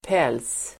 Uttal: [pel:s]